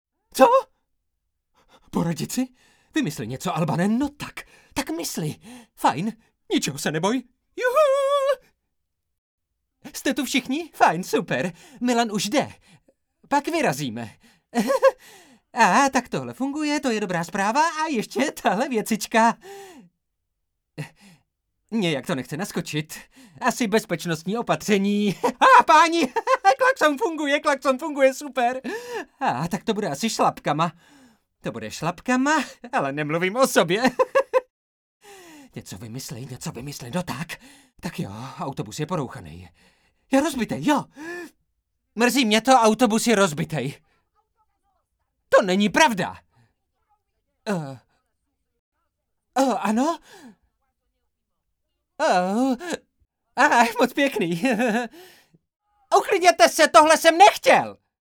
Dabing: